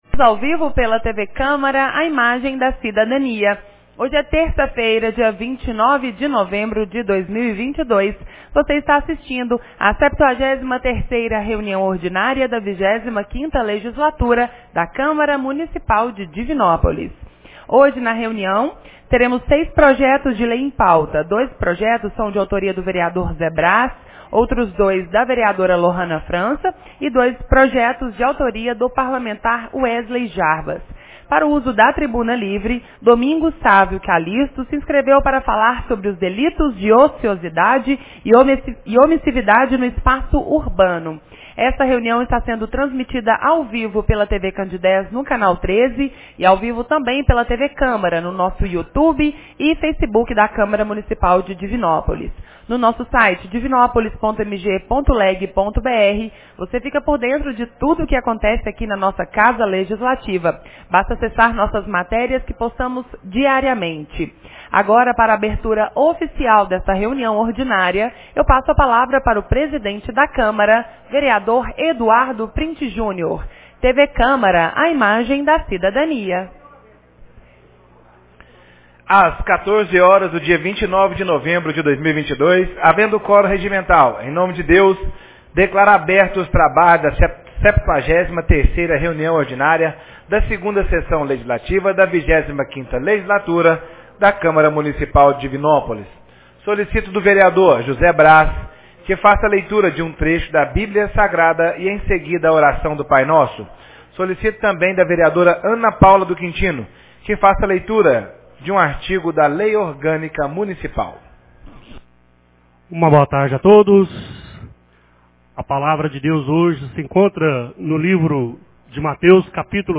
73ª Reunião Ordinária 29 de novembro de 2022